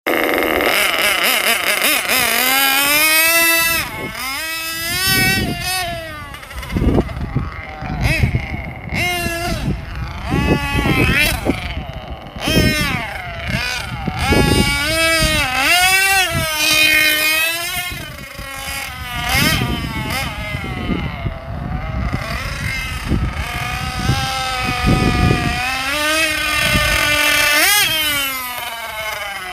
Having fun with new exhaust sound effects free download